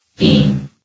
sound / vox_fem / beam.ogg
CitadelStationBot df15bbe0f0 [MIRROR] New & Fixed AI VOX Sound Files ( #6003 ) ...
beam.ogg